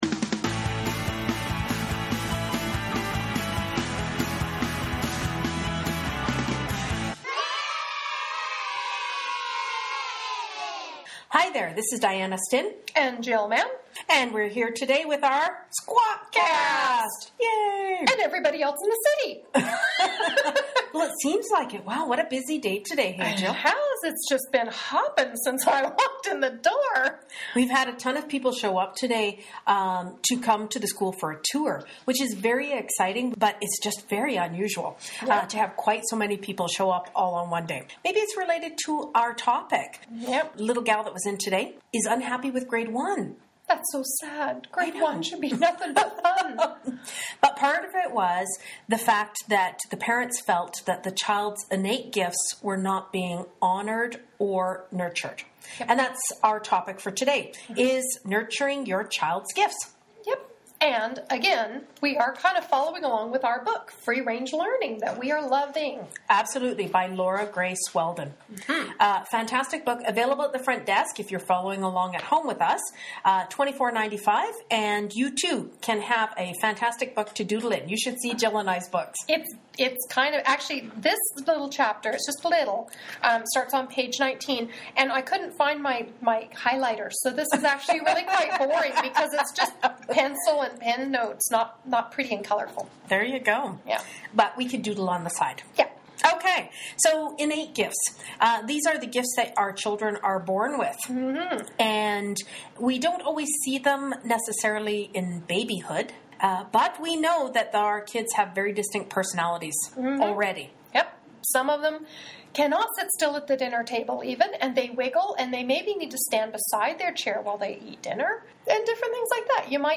This chat is about INNATE GIFTS based on the book by Laura Grace Weldon.